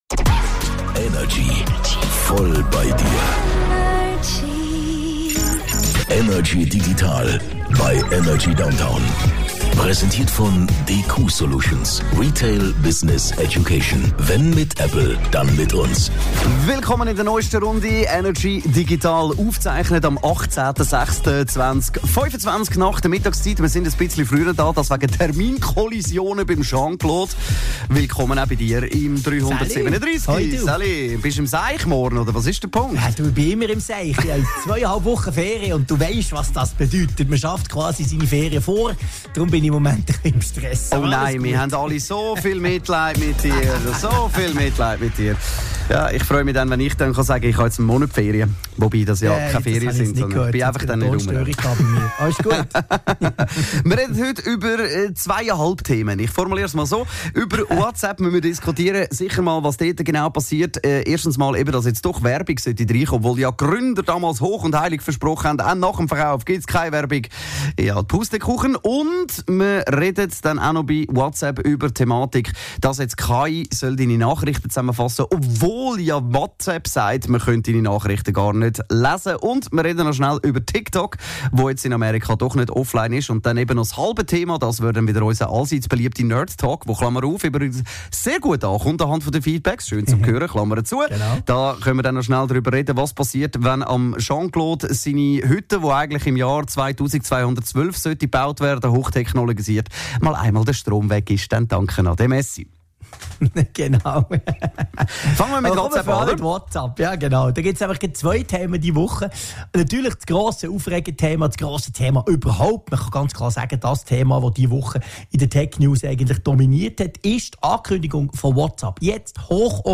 im Energy Studio
aus dem HomeOffice über die digitalen Themen der Woche